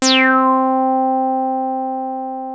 303 C4  4.wav